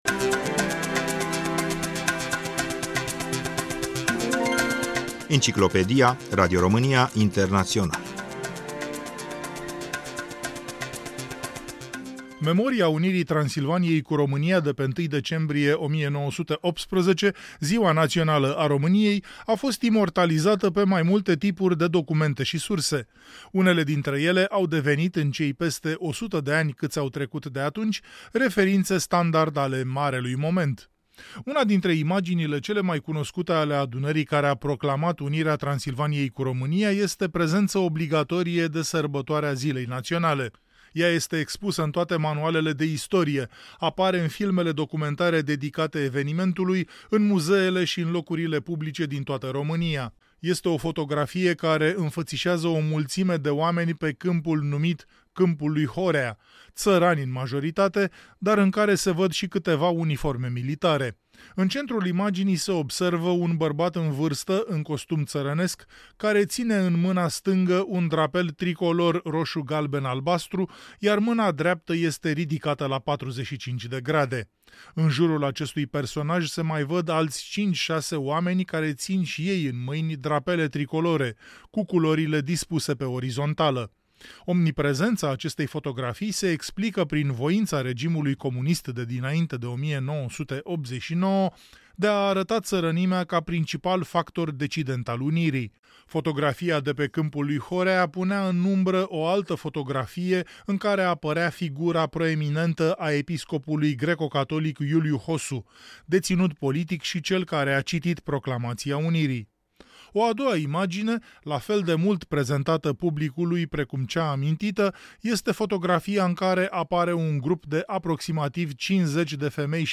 într-un interrviu din anii 1970 din arhiva Centrului de Istorie Orală din Radiodifuziunea Română